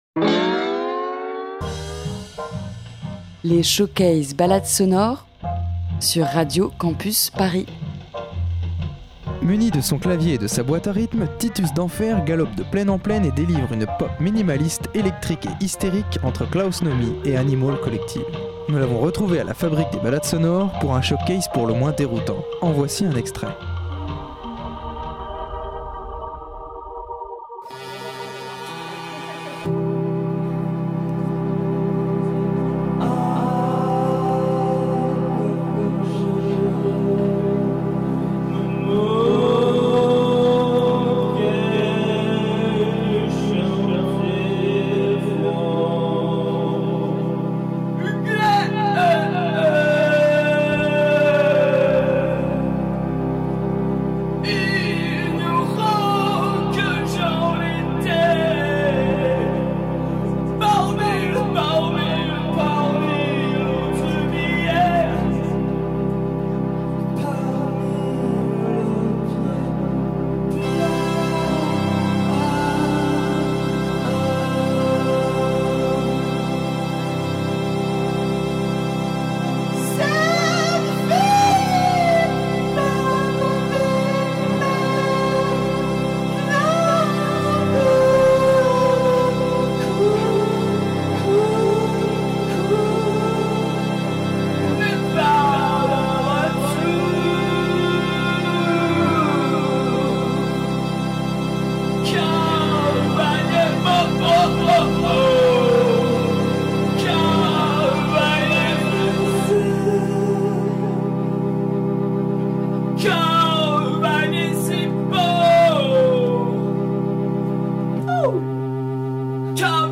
Muni de son clavier et de sa boîte à rythmes
une pop minimaliste, électrique et hystérique